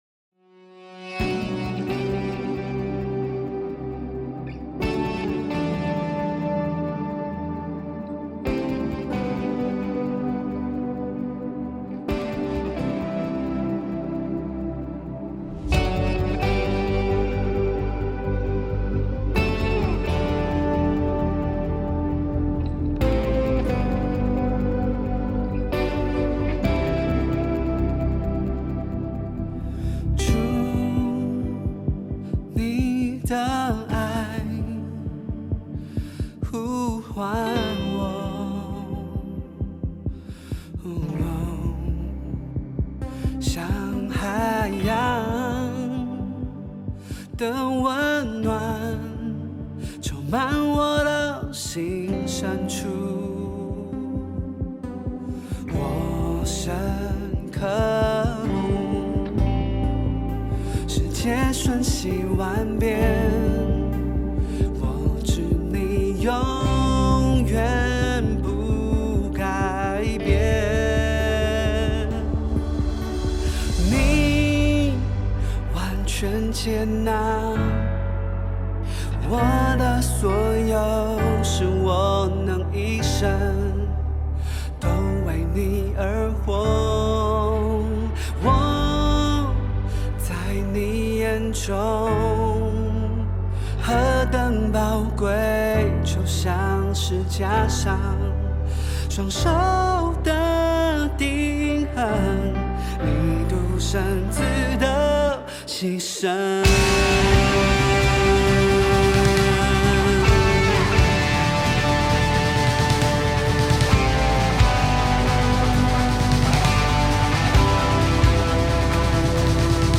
赞美诗